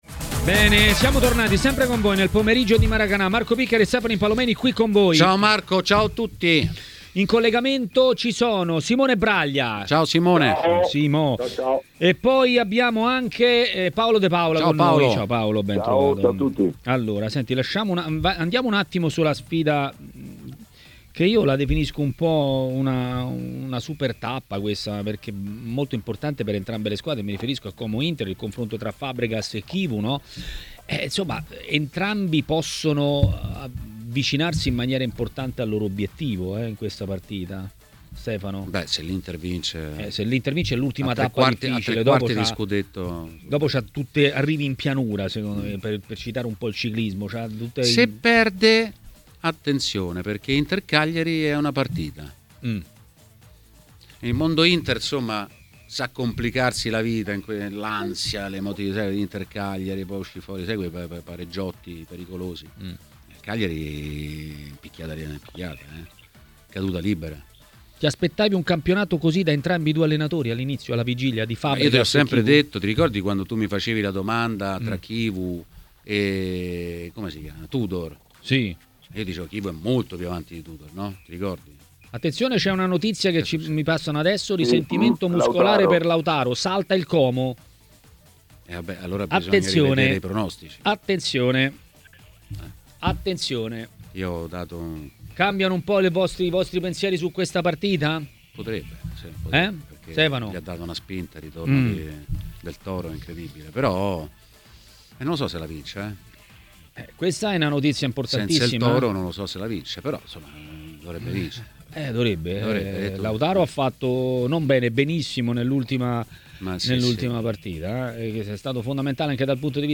Le Interviste
Ospite di Maracanà , nel pomeriggio di TMW Radio